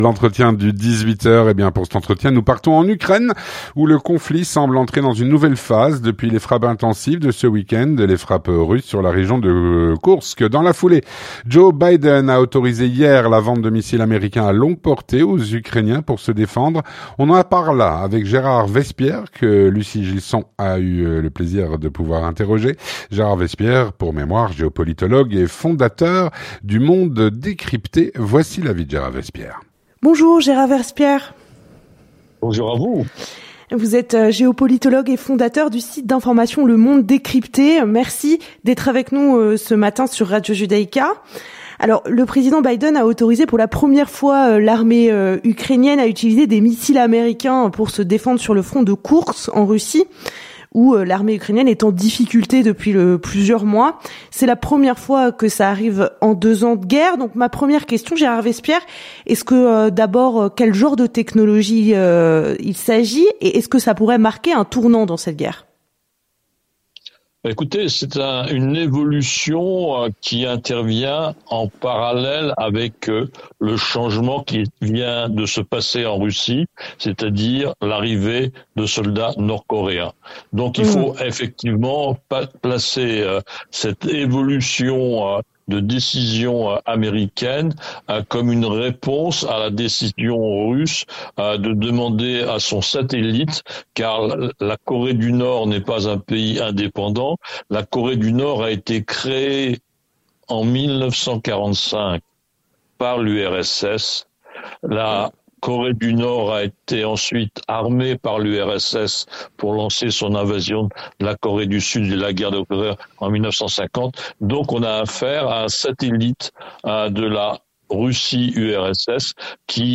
L'entretien du 18H - Guerre Russie-Ukraine : le conflit semble entrer dans une nouvelle phase.